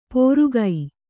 ♪ pōrugai